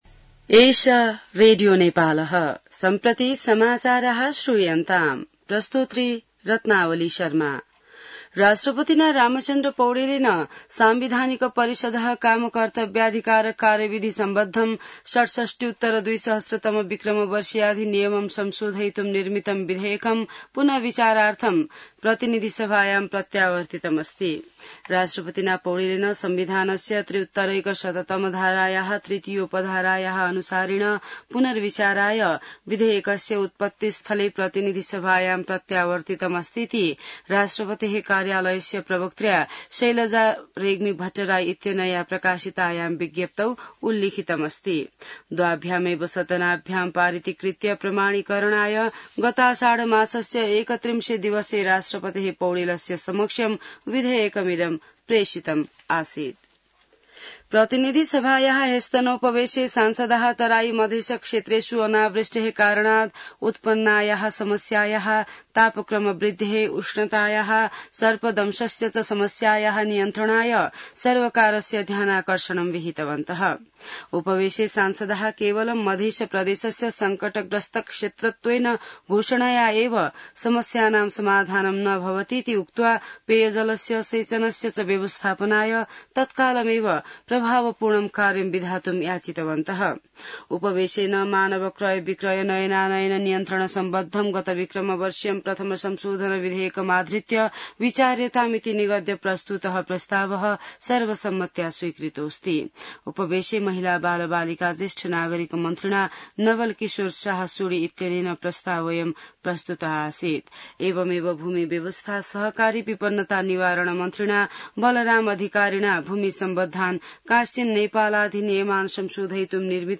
An online outlet of Nepal's national radio broadcaster
संस्कृत समाचार : ९ साउन , २०८२